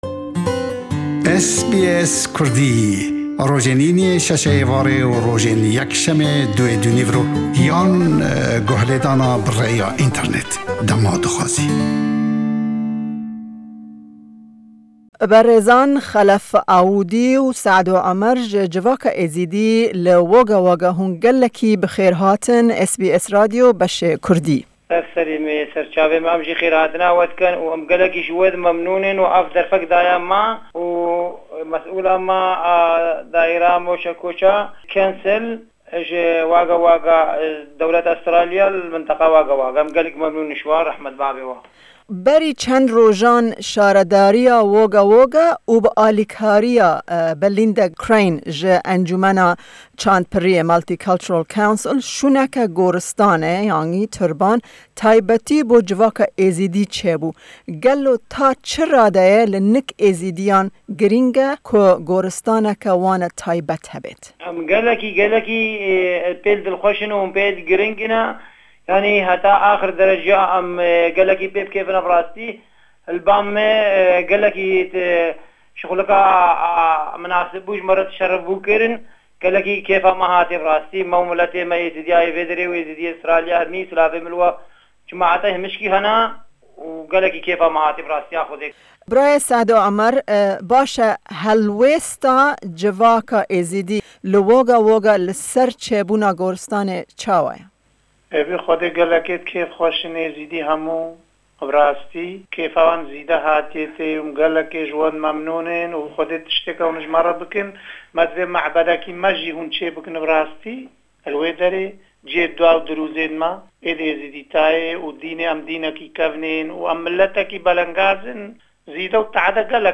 Em bi du endamên ji civaka Êzîdî li Wagga Wagga axifîn